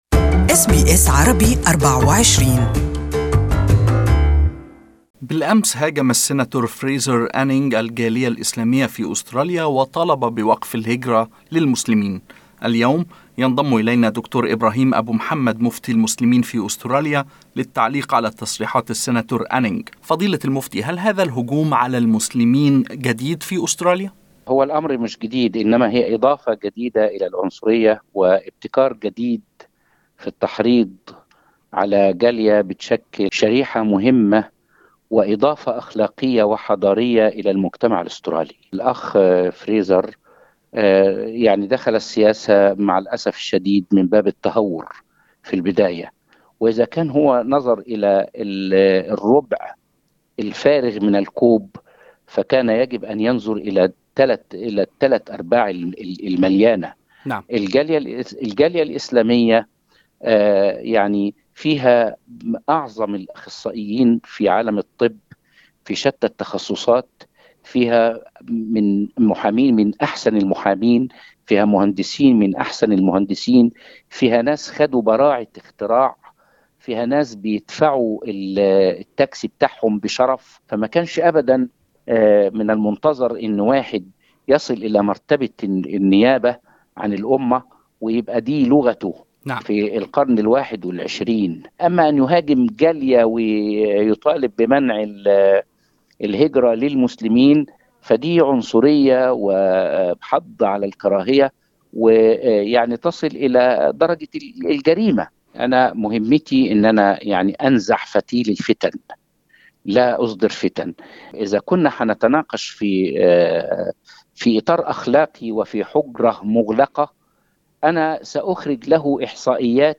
و دحض المفتي المزيد من مزاعم السيناتور الأسترالي فريزر أنينج، ويمكن سماع تصريحات المفتي كاملة في التسجيل الصوتي المرفق أعلاه.